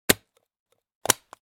Suitcase Open And Close Sound Effect
Description: Suitcase open and close sound effect. Opening an old suitcase by pressing the metal latch mechanism and closing it by pressing the latch or lock, a distinct click sound is heard.
Genres: Sound Effects
Suitcase-open-and-close-sound-effect.mp3